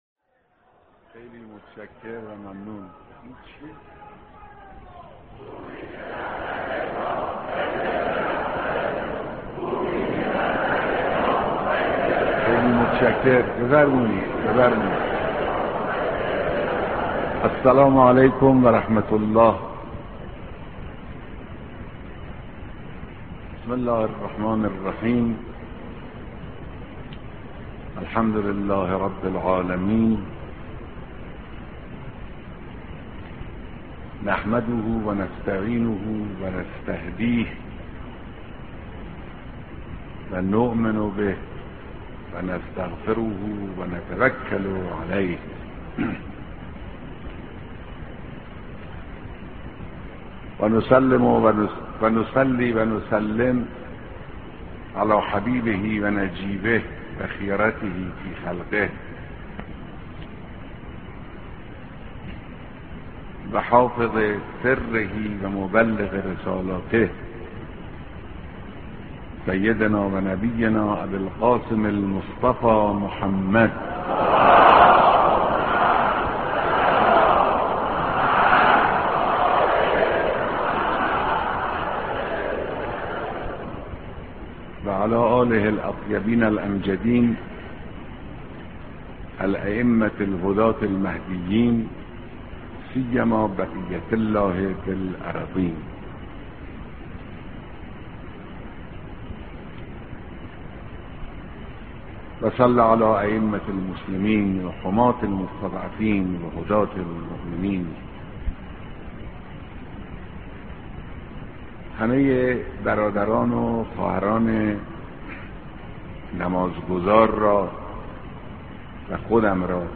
صوت کامل بیانات
بيانات رهبر معظم انقلاب اسلامى در خطبههاى نماز جمعهى تهران